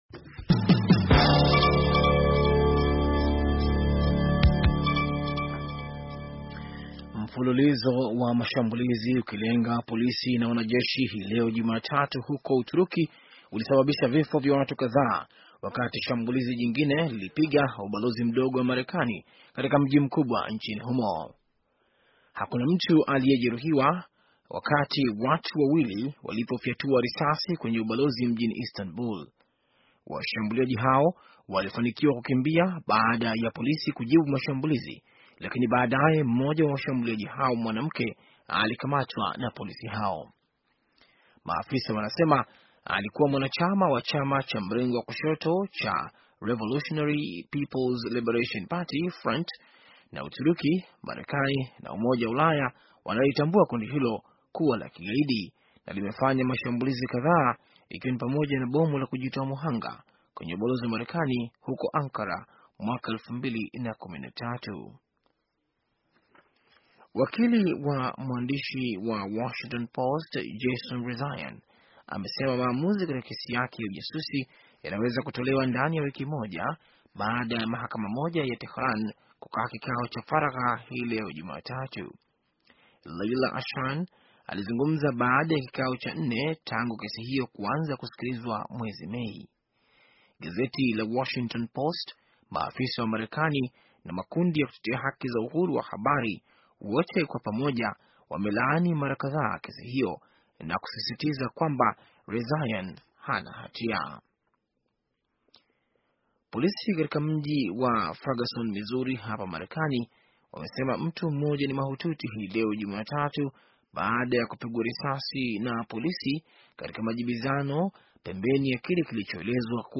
Taarifa ya habari - 4:34